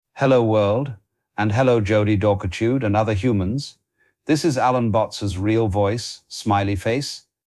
First "Hello World" — the moment the voice worked